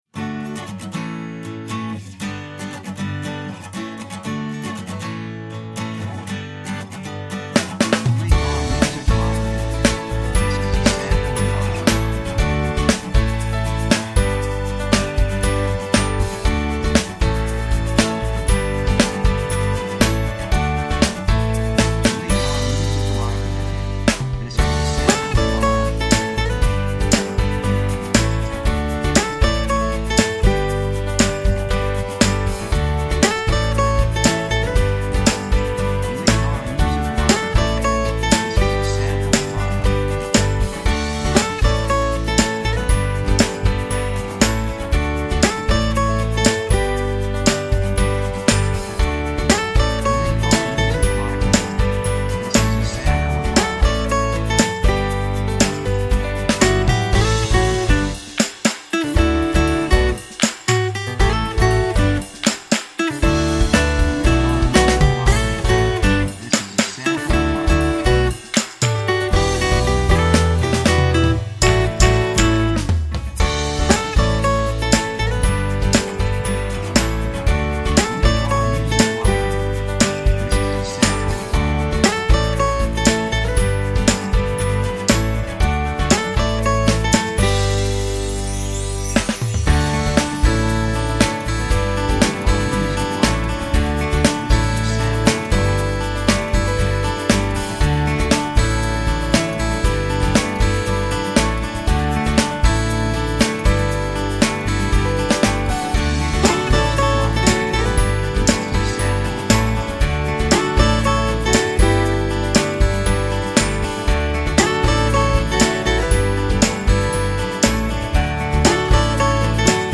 3:02 118 プロモ, アコースティック